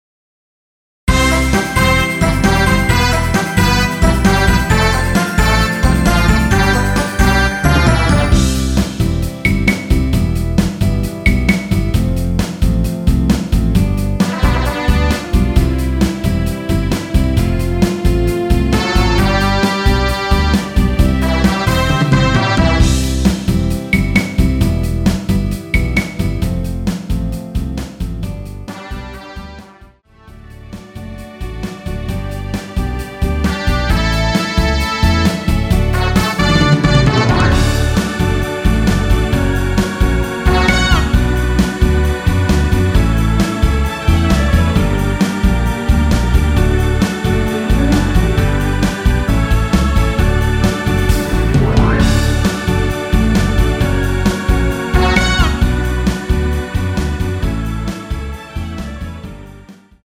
원키에서(-5)내린 MR입니다.
앞부분30초, 뒷부분30초씩 편집해서 올려 드리고 있습니다.